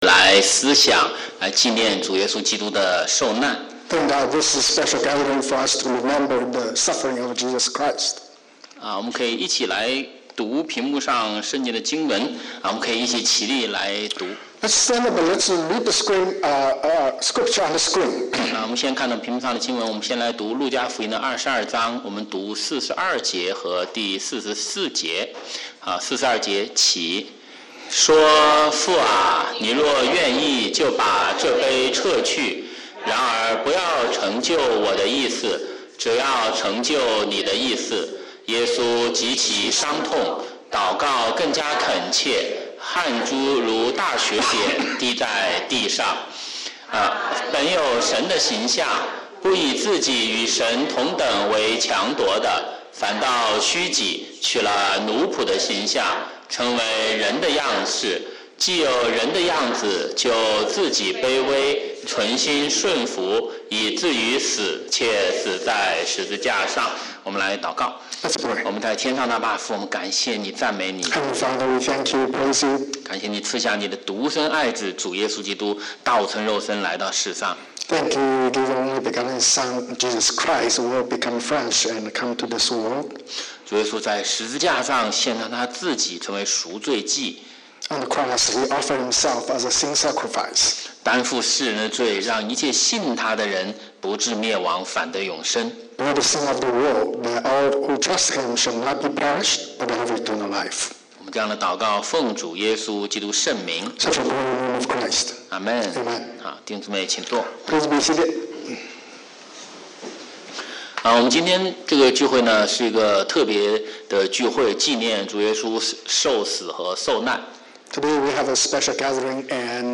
周五晚上查经讲道录音